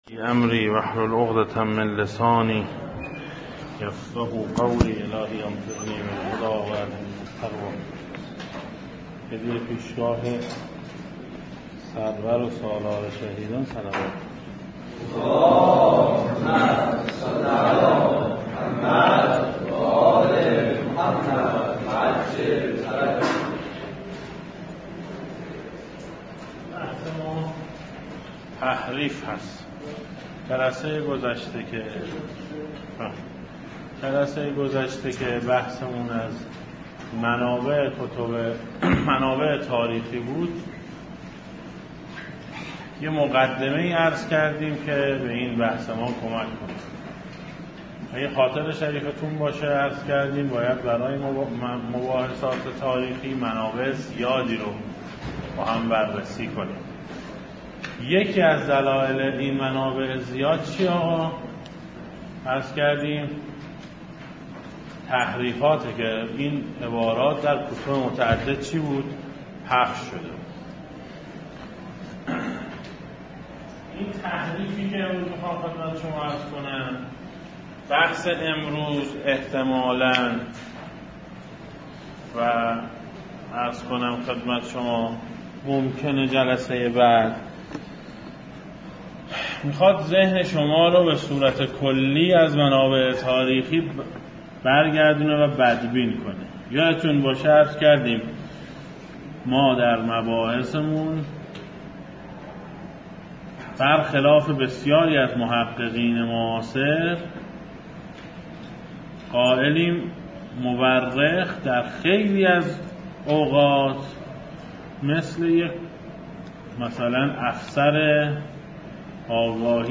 در دانشگاه امام صادق علیه السلام روز یکشنبه 11 مهر 1395 برگزار شد که مشروح این کلاس تقدیم می گردد.
دسته: دروس, سیره اهل بیت علیهم السلام